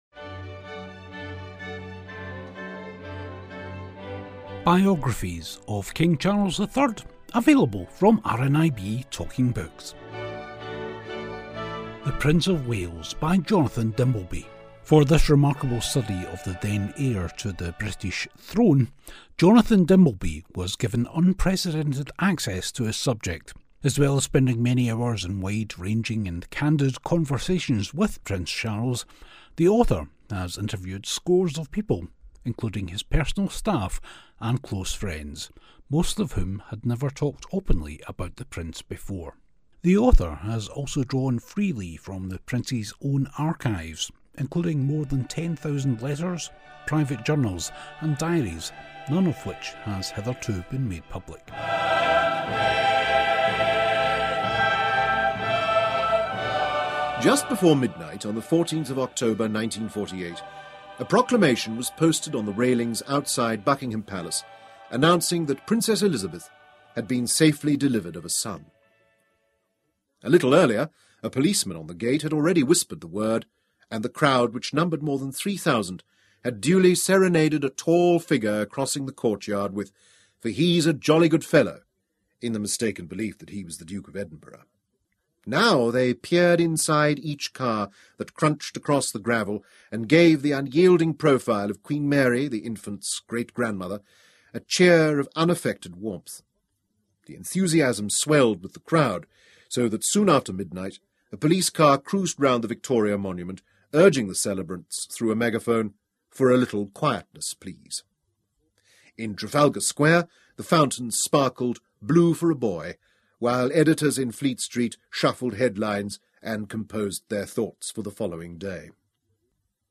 Today he's reading some Biographies